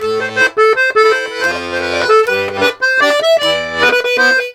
Index of /90_sSampleCDs/USB Soundscan vol.40 - Complete Accordions [AKAI] 1CD/Partition E/02-160LARIDE